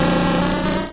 Cri de Dardargnan dans Pokémon Rouge et Bleu.